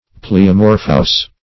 Pleomorphous \Ple`o*mor"phous\, a. Having the property of pleomorphism.
pleomorphous.mp3